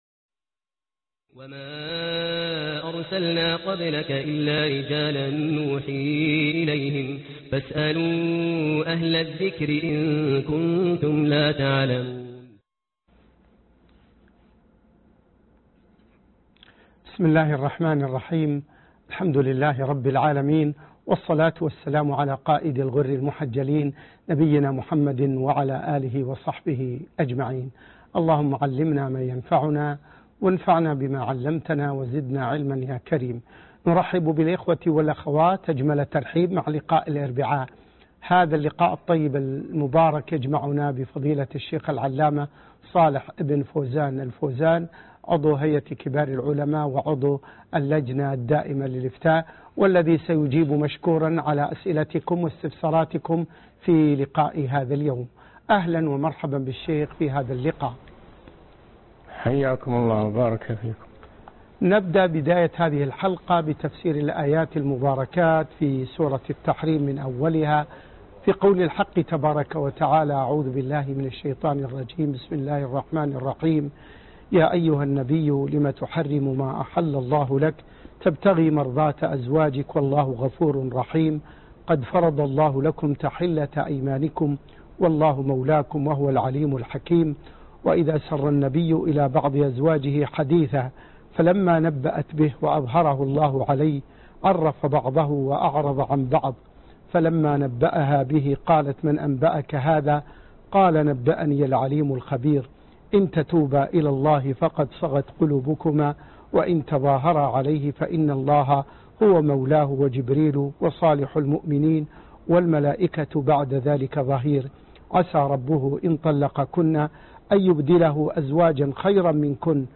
فتاوي مع تفسير أوائل سورة التحريم(14/6/1434 هـ)(فتاوي علي الهواء) - الشيخ صالح بن فوزان الفوازان